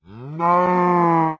sounds / mob / cow / say3.ogg